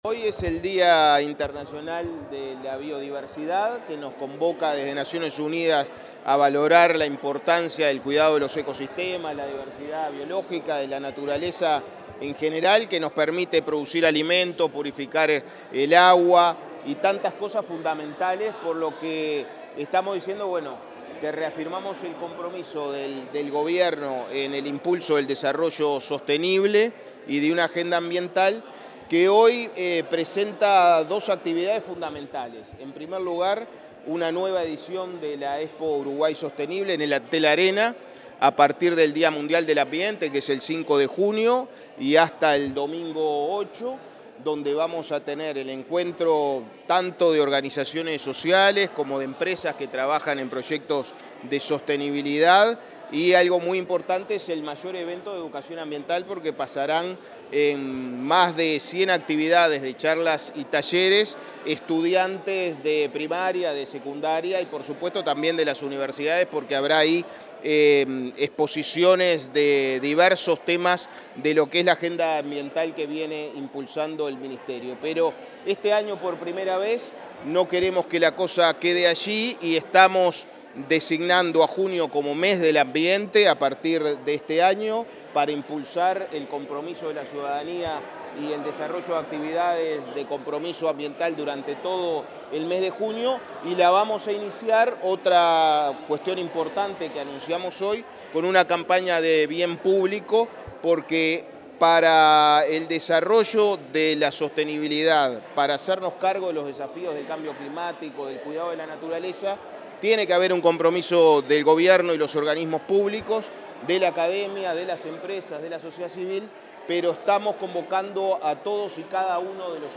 Declaraciones a la prensa del ministro de Ambiente, Edgardo Ortuño
El ministro de Ambiente, Edgardo Ortuño, dialogó con los medios informativos tras la presentación de la Expo Uruguay Sostenible 2025.